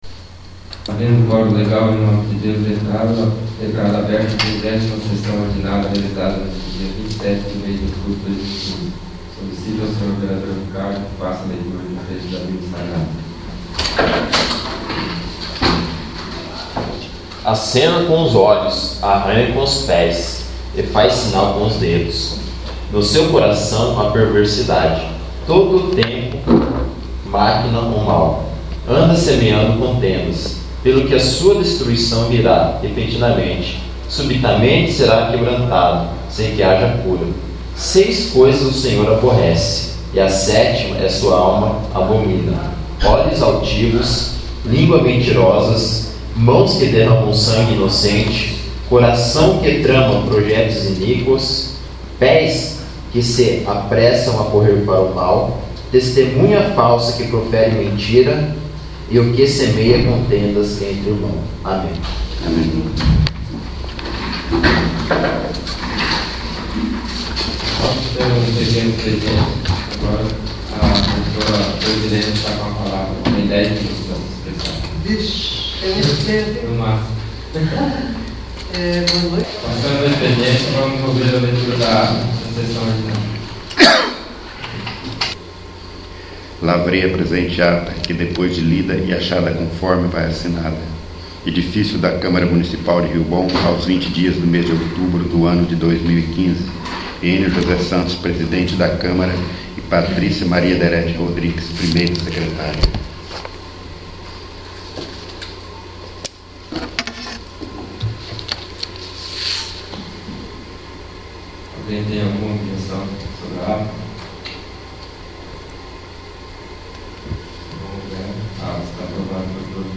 30º. Sessão Ordinária